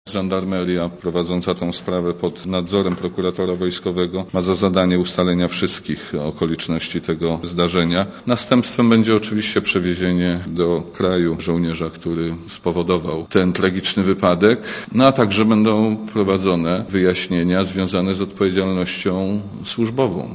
Posłuchaj ministra Szmajdzińskiego